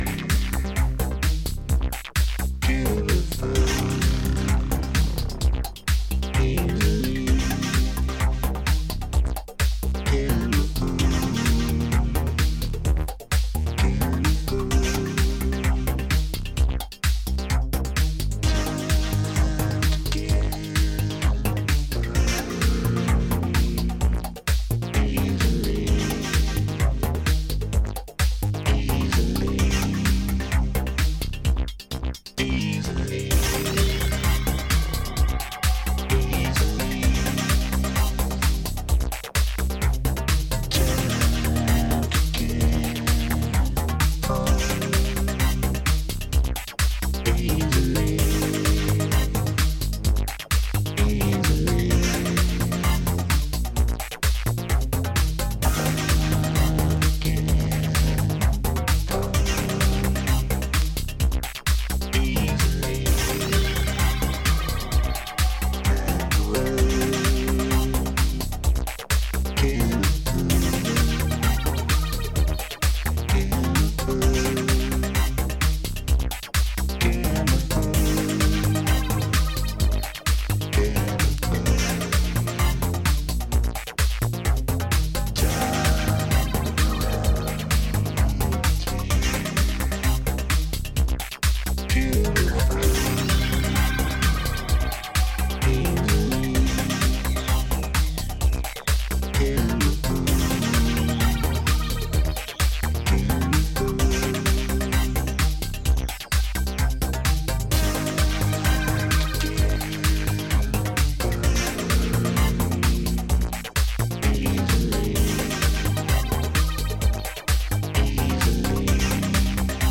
躍動感あふれるエレクトロ・サウンドを展開しています